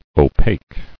[o·paque]